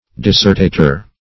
Search Result for " dissertator" : The Collaborative International Dictionary of English v.0.48: Dissertator \Dis"ser*ta`tor\, n. [L.: cf. F. dissertateur.] One who writers a dissertation; one who discourses.